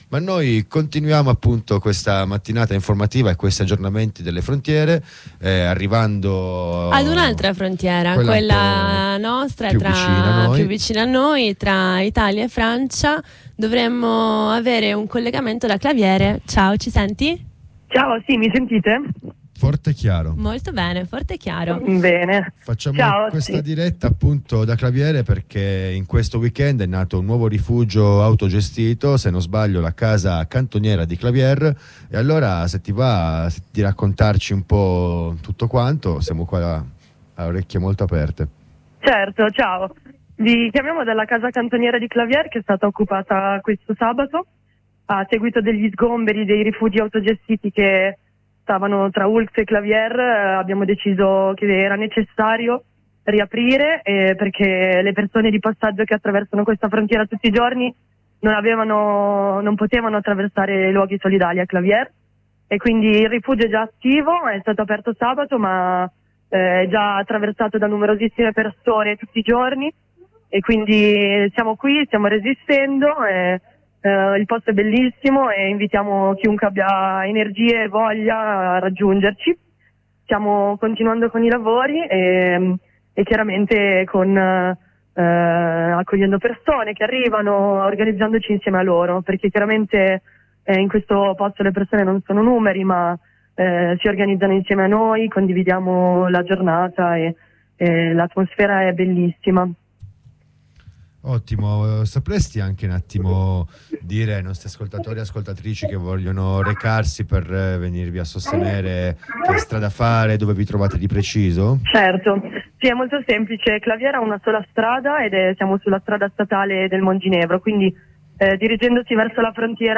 Ieri avevamo sentito una compagna dalla nuova occupazione della Casa Cantoniera di Claviere, alla frontiera con la Francia.